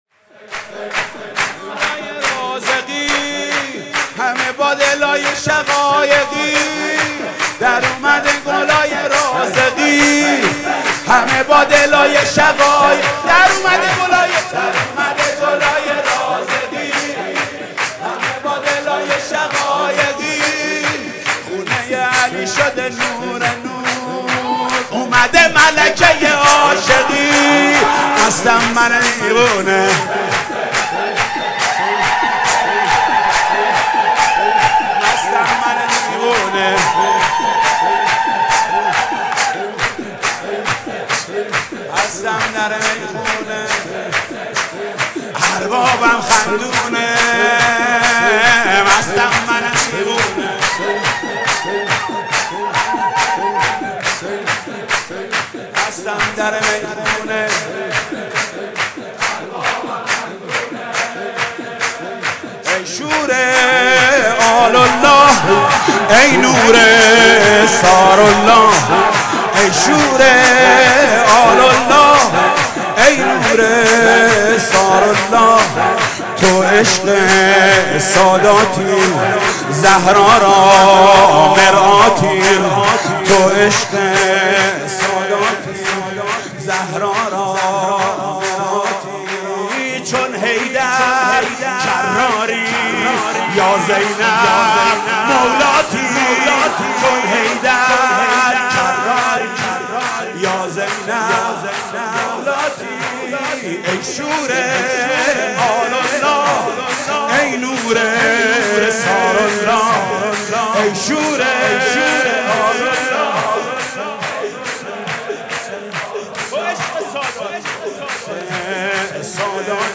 ولادت حضرت زینب (سرود)